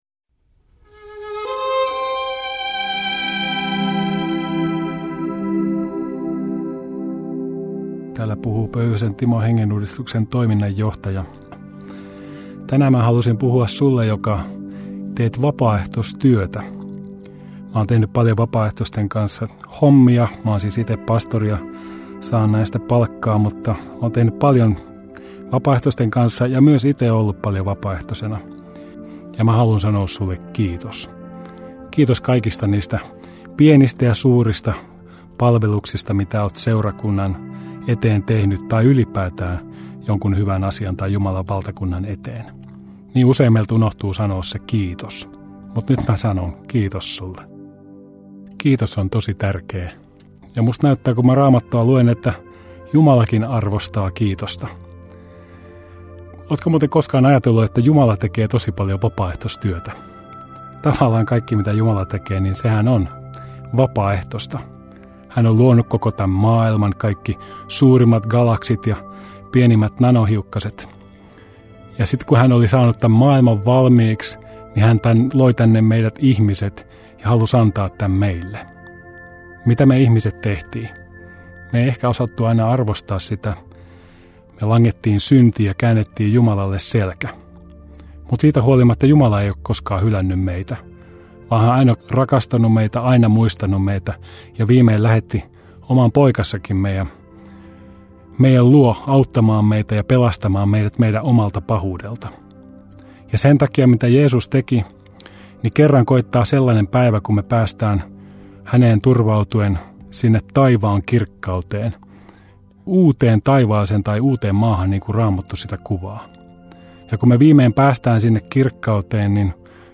Radio Dei lähettää FM-taajuuksillaan radiohartauden joka arkiaamu kello 7.50. Hartaus kuullaan uusintana iltapäivällä kello 17.05.
Radio Dein aamuhartauksien pitäjinä kuullaan laajaa kirjoa kirkon työntekijöitä sekä maallikoita, jotka tuntevat radioilmaisun omakseen.
Lokakuun 2018 radiohartaudet: